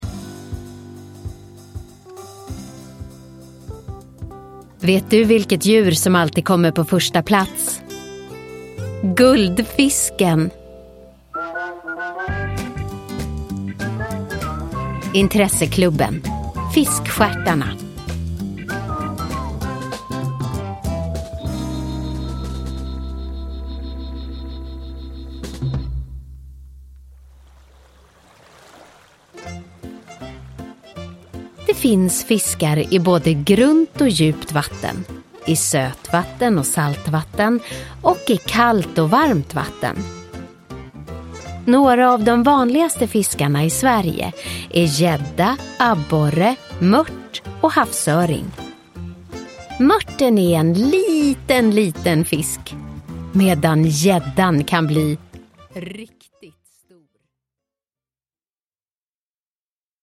Fiskstjärtarna – Ljudbok – Laddas ner